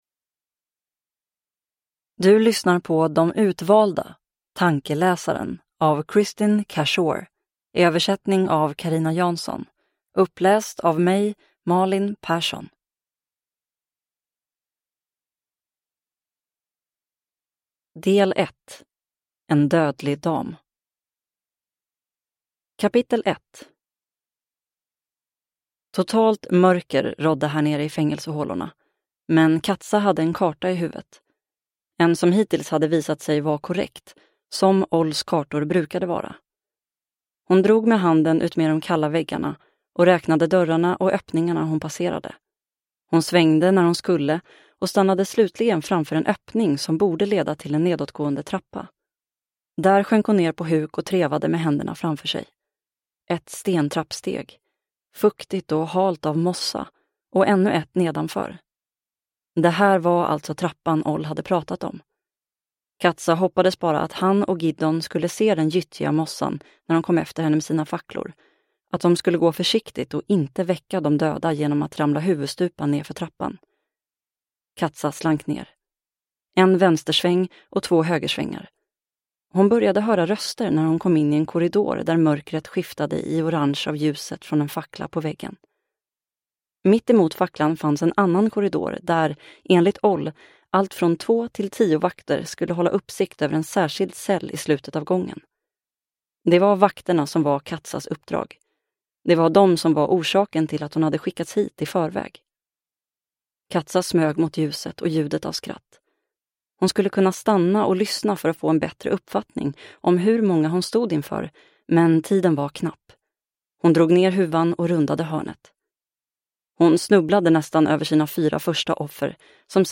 Tankeläsaren – Ljudbok – Laddas ner